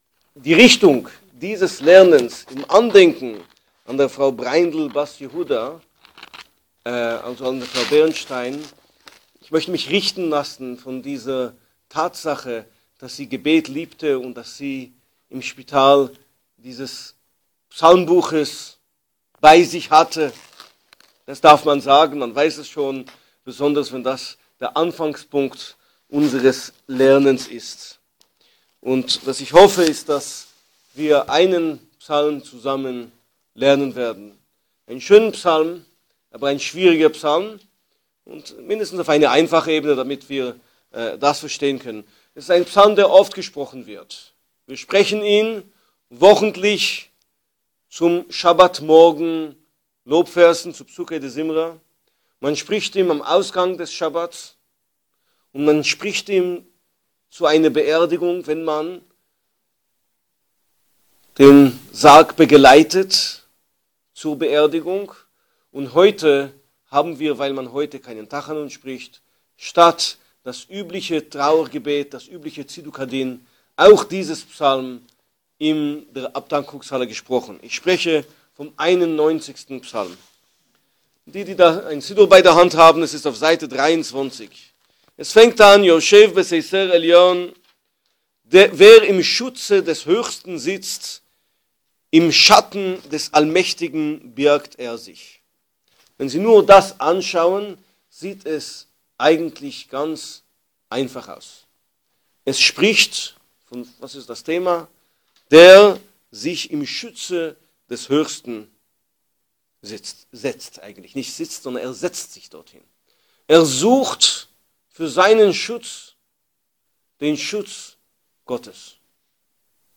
Zum ersten Schiwa'-Lernen wurde der schwierige, aber schöne und tiefe Psalm 91 ausgelegt. Anbei die Aufnahme dieses Lernens.